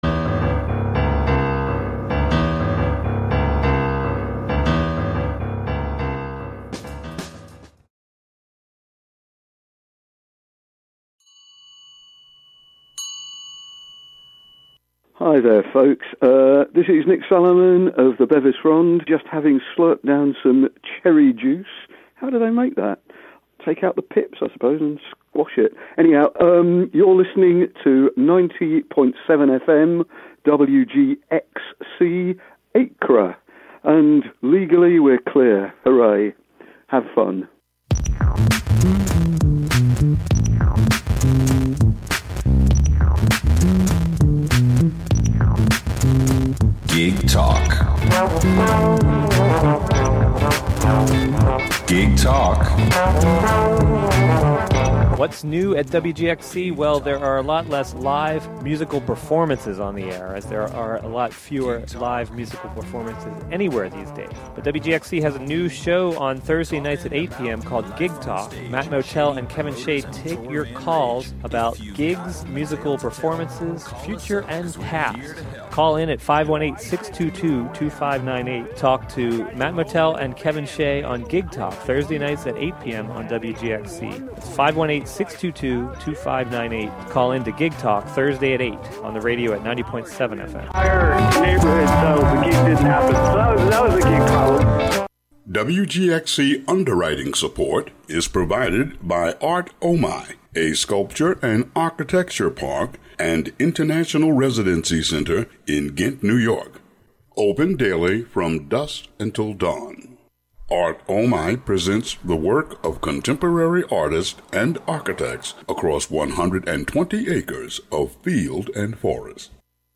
Broadcast live from HiLo in Catskill.